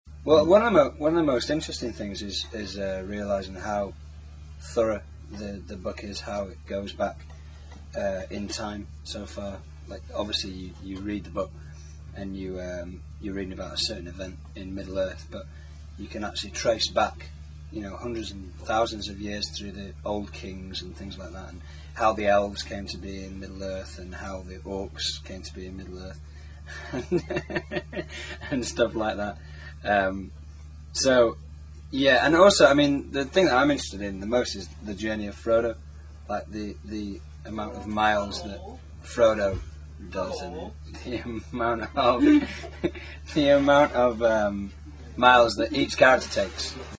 hobb_interview_001.mp3